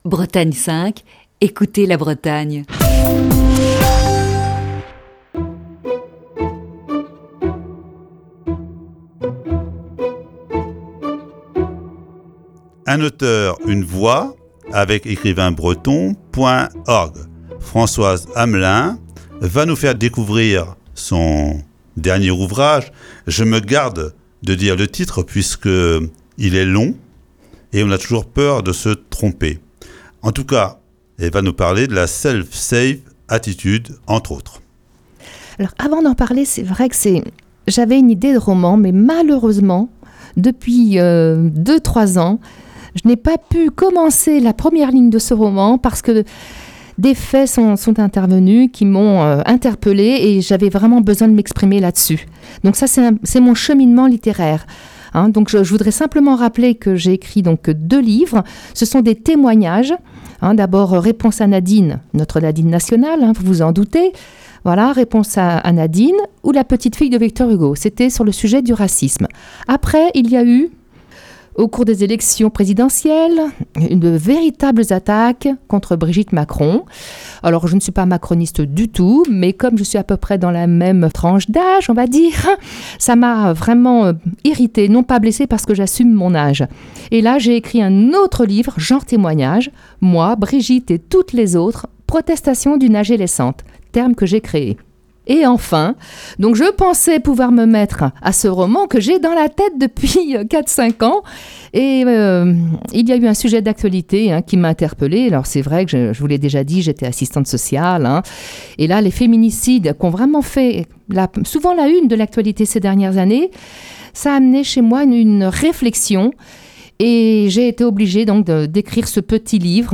Chronique du 3 janvier 2020.